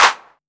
TR 808 Clap 02.wav